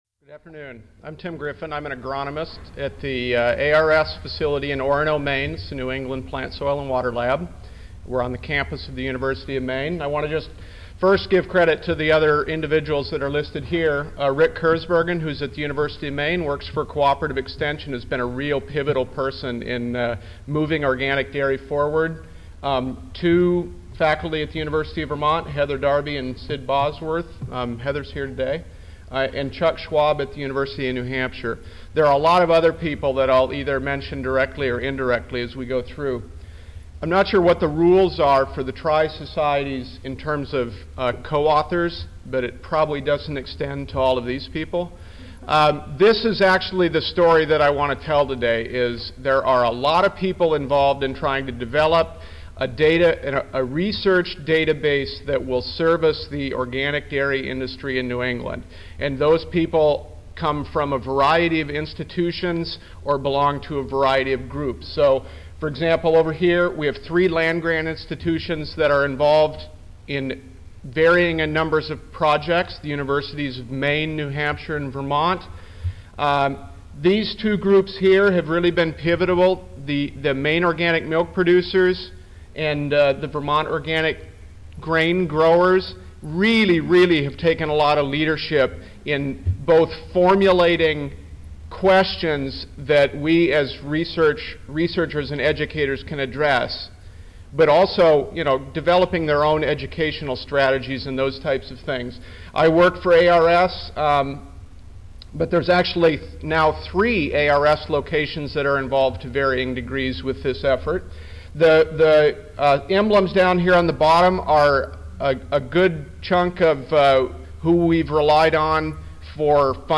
Back to Symposium--Building Sustainable Ecosystems Through Organic Agricultural Research and Practice: II Back to A08 Integrated Agricultural SystemsBack to The ASA-CSSA-SSSA International Annual Meetings (November 4-8, 2007)
Recorded presentation